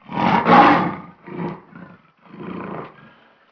Lion Roar Download
Lionroar.mp3